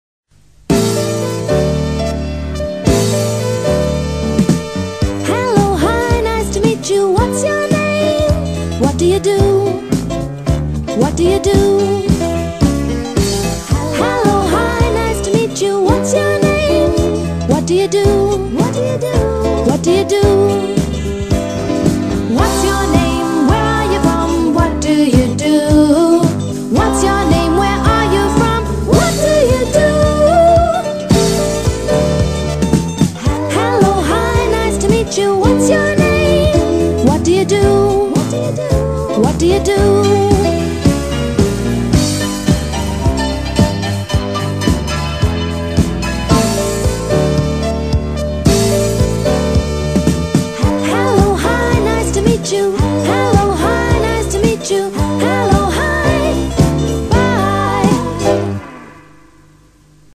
Aprende Gramática Cantando